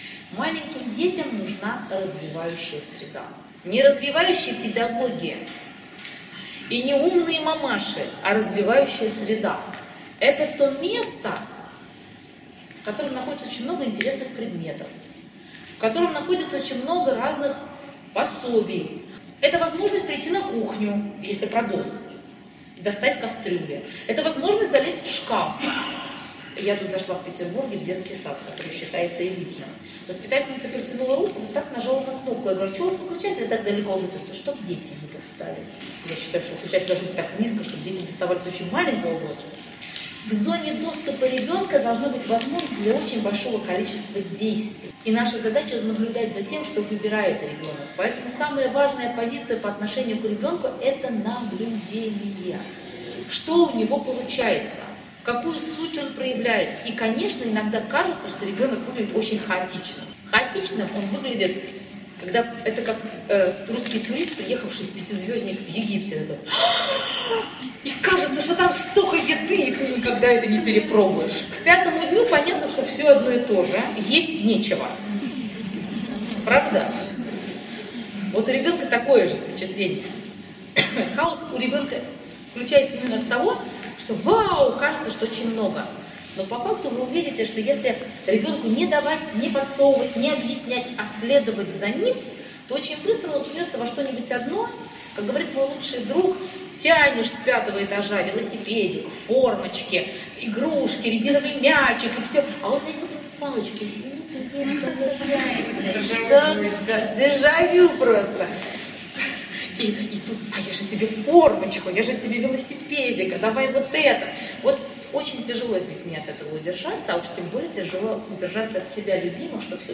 Аудио - небольшой фрагмент беседы